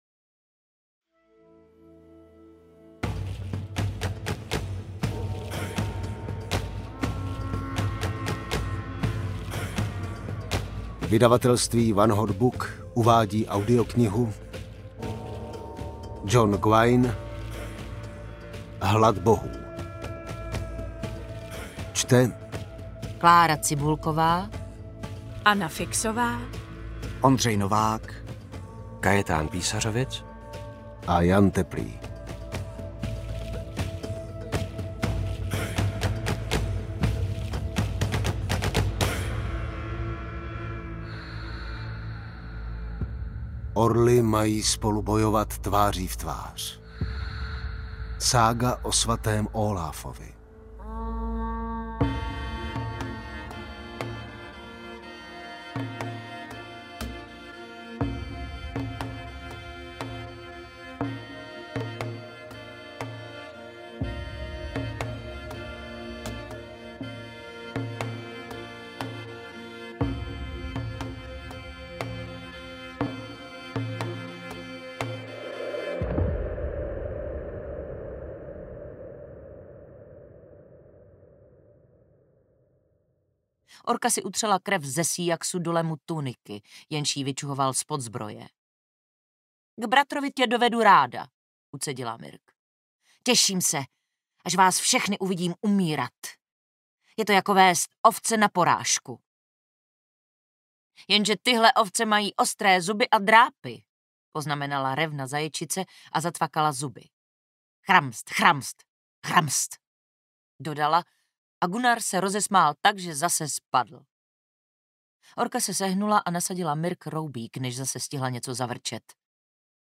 Hlad bohů audiokniha
Ukázka z knihy